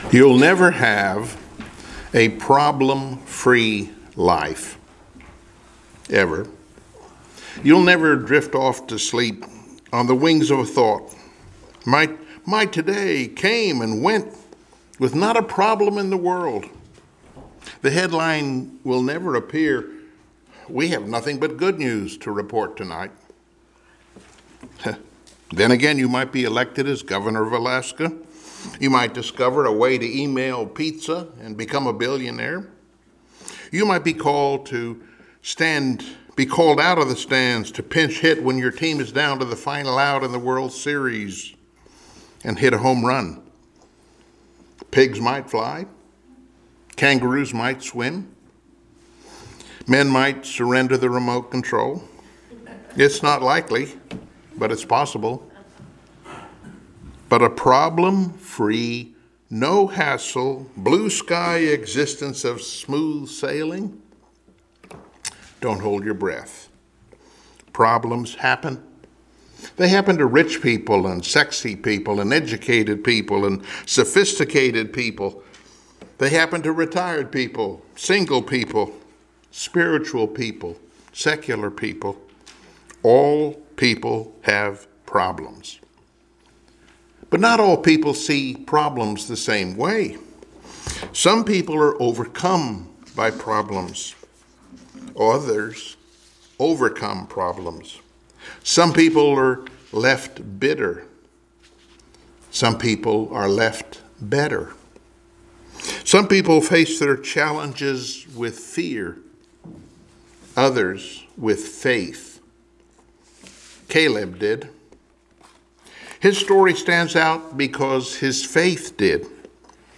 Joshua Passage: Joshua 14:6-15 Service Type: Sunday Morning Worship Topics